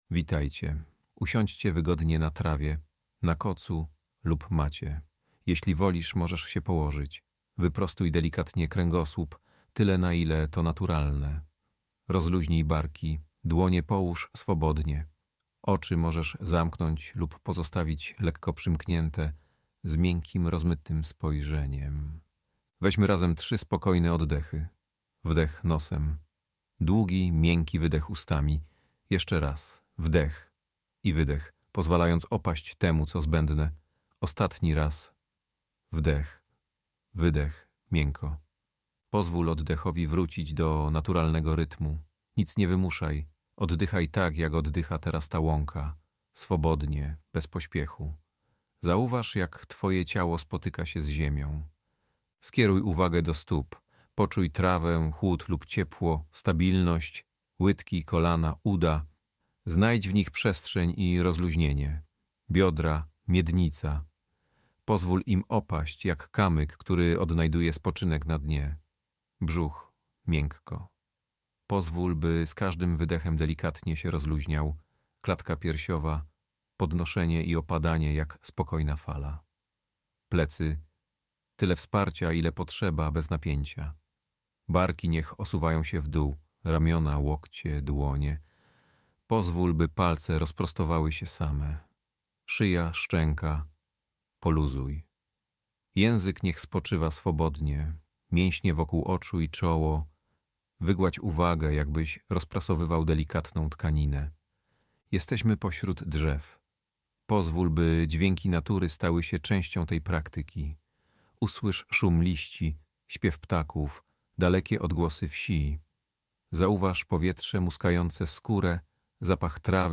Spersonalizowane medytacje prowadzone - doświadczenie łączące naturę, technologię i wspólnotę w unikalnej podróży do ciszy.
00-medytacja-grupowa.MP3